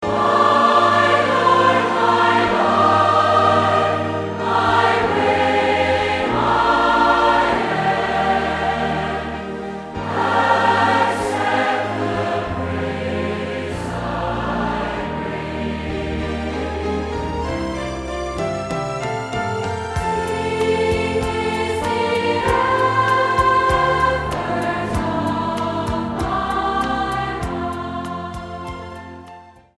STYLE: MOR / Soft Pop
300 singers from over 90 churches in Central Scotland